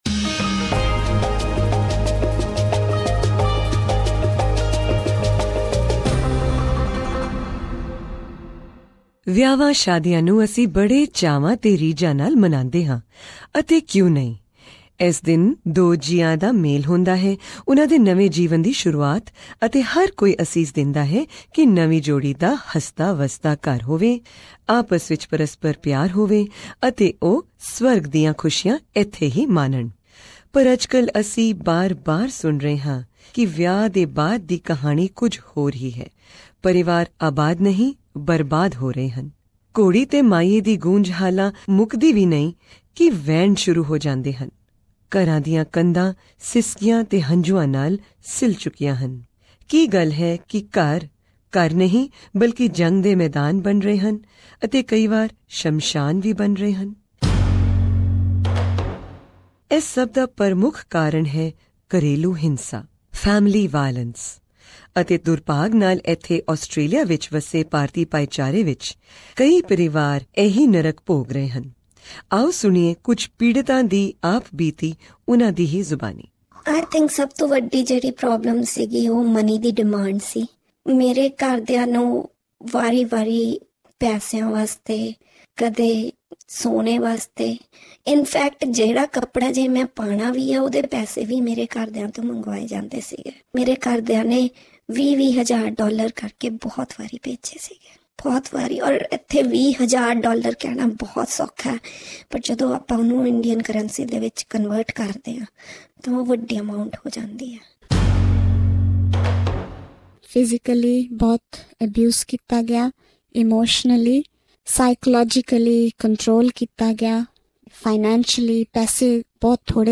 For legal reasons some names have been changed and some voices have been altered, so that the victims can't be identified.
In this episode, you hear from four victims and the sister of a victim who is now deceased.